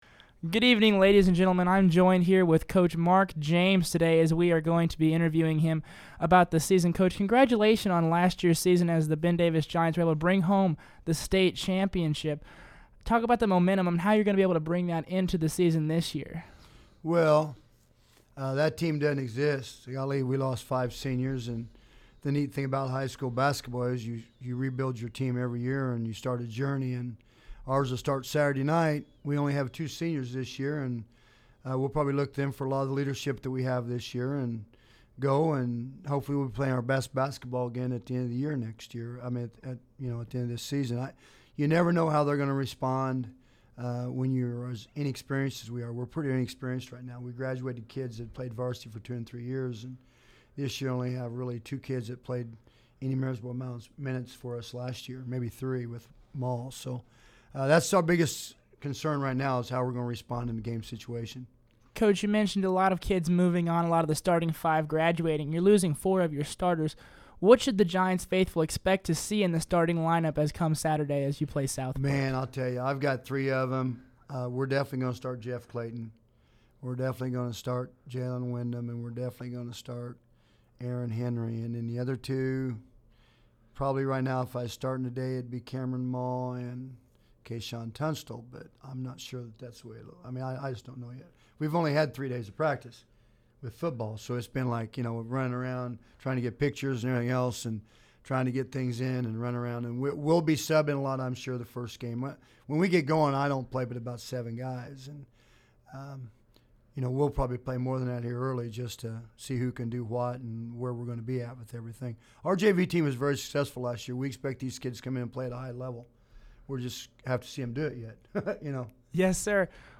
sit-down interview